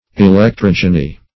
Search Result for " electrogeny" : The Collaborative International Dictionary of English v.0.48: Electrogeny \E`lec*trog"e*ny\, n. [Electro- + Gr.